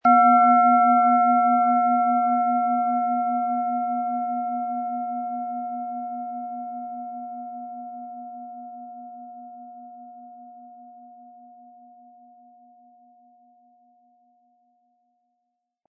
Seit Generationen werden in dem Meisterbetrieb in Indien Klangschalen hergestellt, aus dem diese Lilith Planetenton-Klangschale stammt.
Spielen Sie die Schale mit dem kostenfrei beigelegten Klöppel sanft an und sie wird wohltuend erklingen.
MaterialBronze